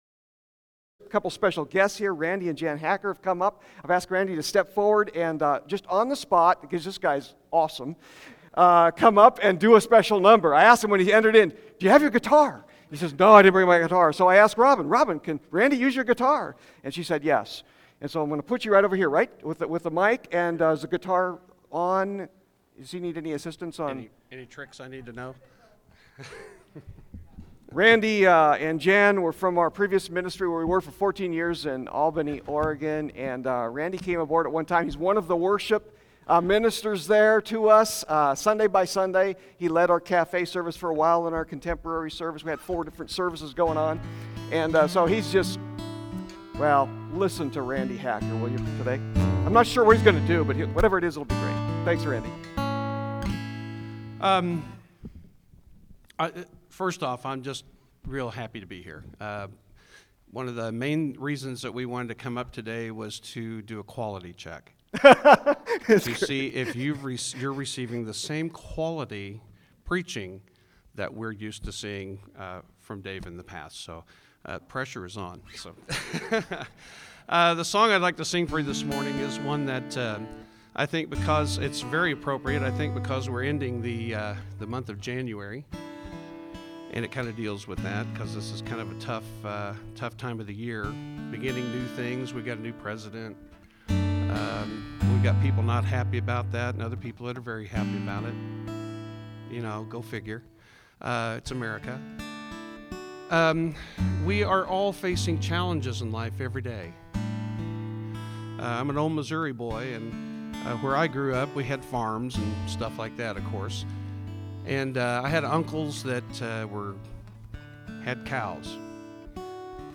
TBC-Sermon-iMovement.mp3